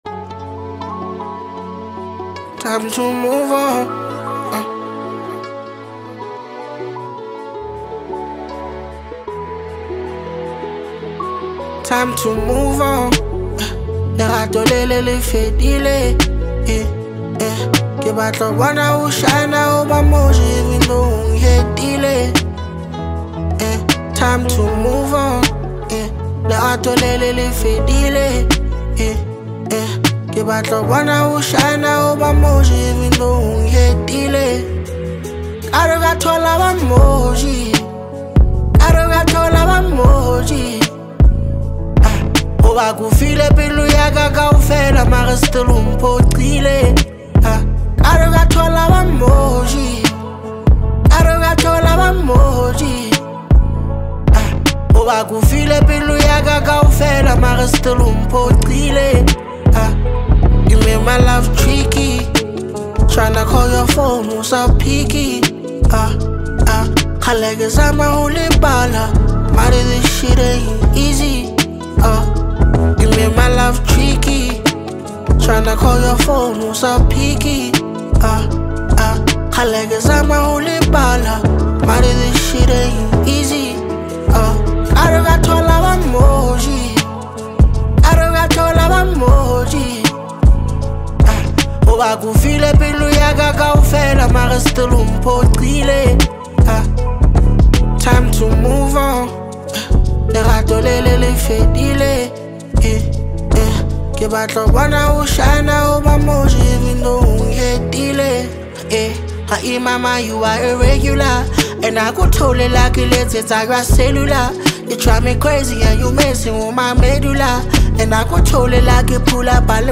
” which is a collection of seven incredible Hip Hop tracks.
It has got everything from catchy beats to heartfelt lyrics.